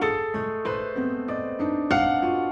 Index of /musicradar/gangster-sting-samples/95bpm Loops
GS_Piano_95-A2.wav